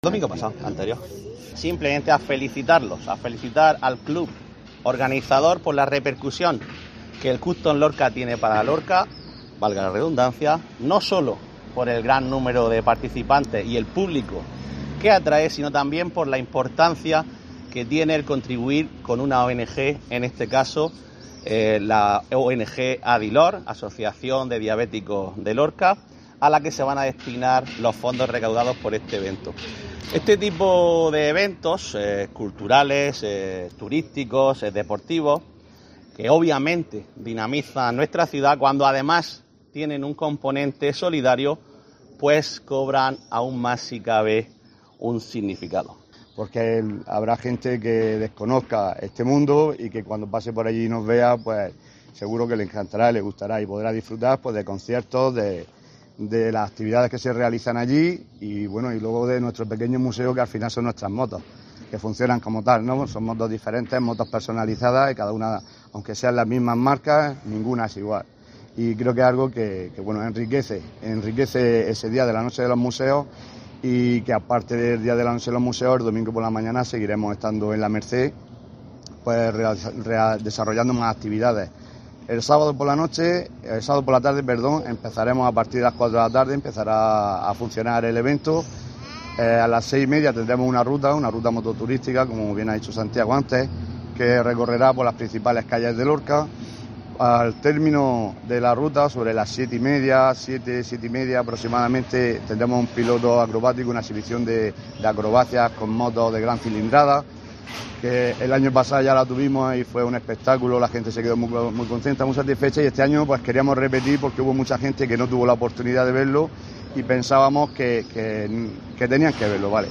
Juan Miguel Bayonas, concejal de deportes Ayto Lorca